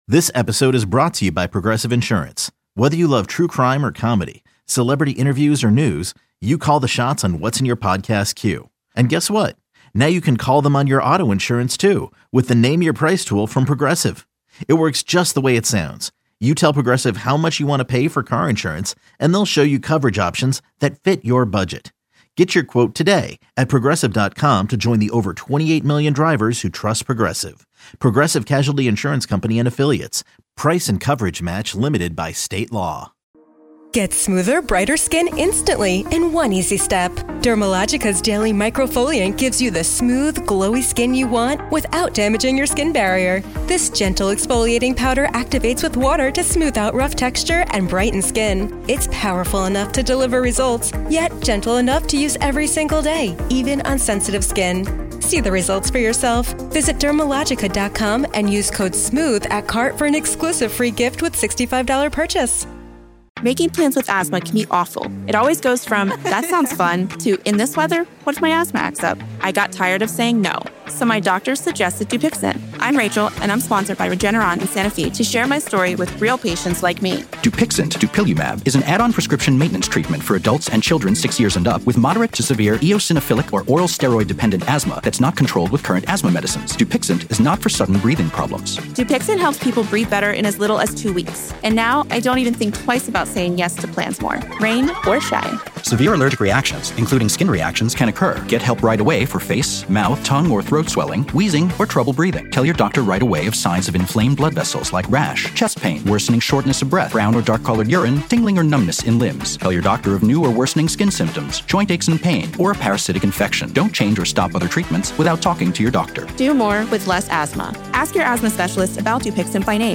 Hosted by former NFL MVP Boomer Esiason and seasoned radio host Gregg “Gio” Giannotti, your go-to morning sports talk show covers the hottest topics in the NFL, MLB, NBA and college sports and breaks down everything New York — including the Giants, Jets, Yankees, Mets, Knicks, Nets, Rangers, and Islanders.